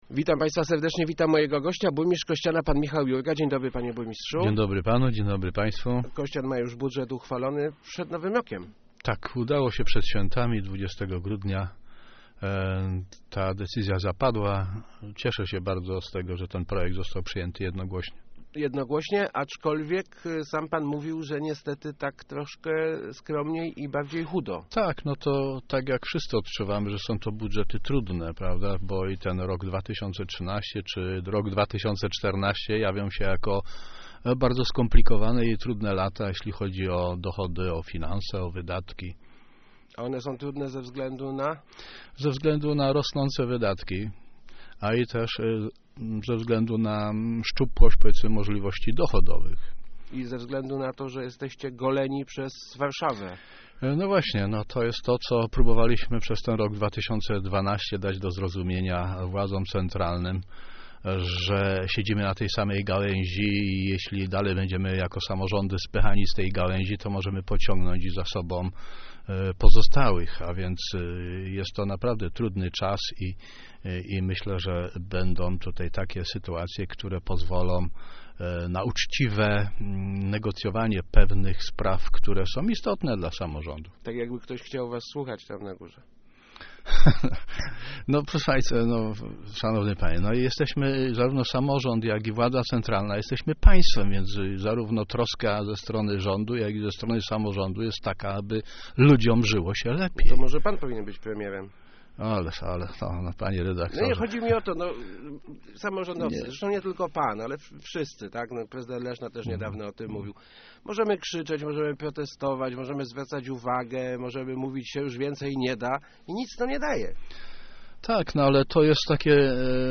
Start arrow Rozmowy Elki arrow Kościańskie plany na przyszłość